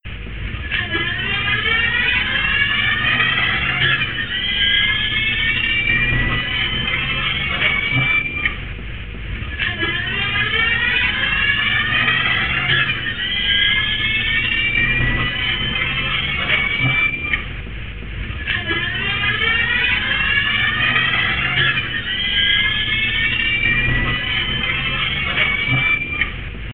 First gear sounds like a siren, and second gear I can’t compare to anything; nothing else really sounds like this. Third was silent, probably 1:1, and fourth gear had a peculiar whirr about it, too. Here’s the closest I could come to isolated audio of an AT-540 from a standing start:
AT-540_1st-2nd_Gear.mp3